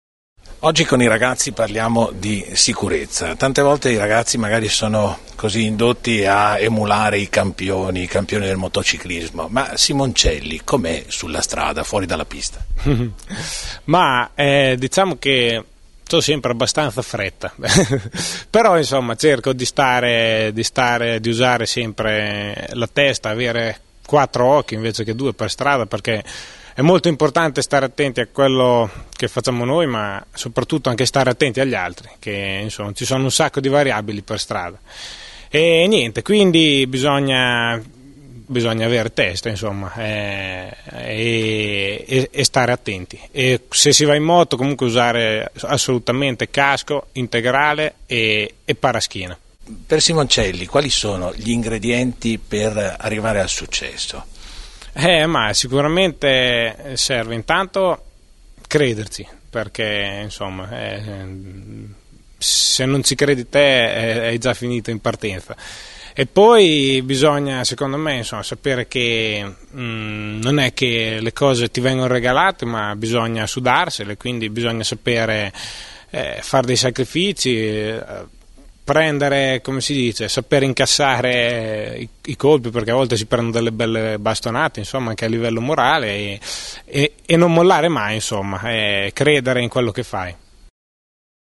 Il campione motociclistico MARCO SIMONCELLI ospite dello “Spazio Giovani”, dedicato agli studenti dell Scuole superiori della provincia di Vicenza, parla di sicurezza stradale e di spinta… motivazionale.